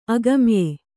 ♪ agamye